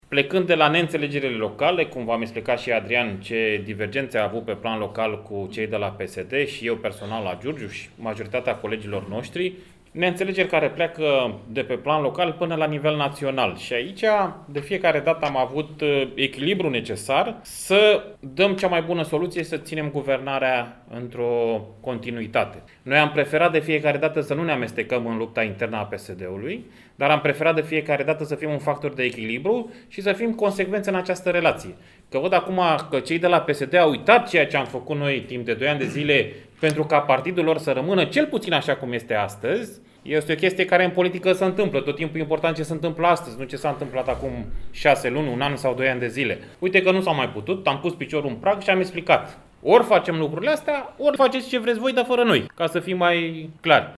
În cadrul unei conferințe de presă s-a discutat printre altele și despre rezultatele obținute de către organizația județeană ALDE, care după cum a declarat Toma Petcu, au demonstrat faptul că la Buzău, echipa formată s-a dovedit a fi una închegată, cu potențial pe viitor.